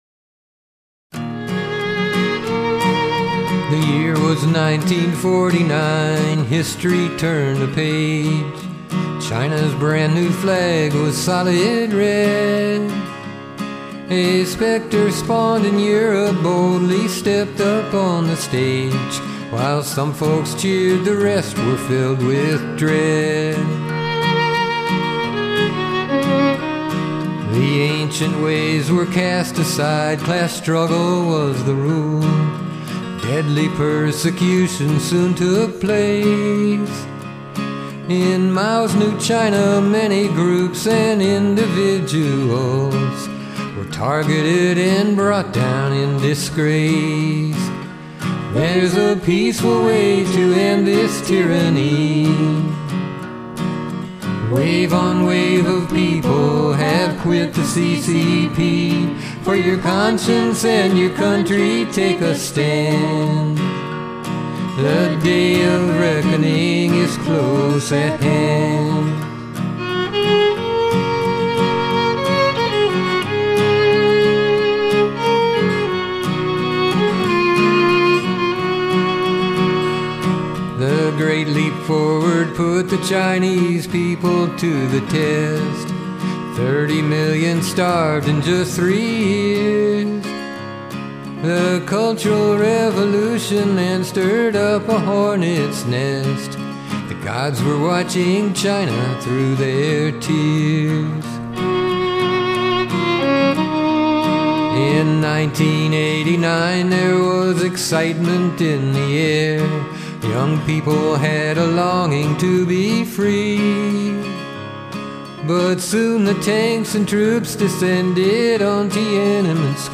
Vocals
Guitar
Violin